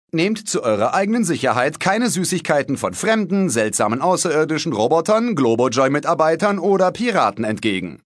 picture x Onkel Mortimer: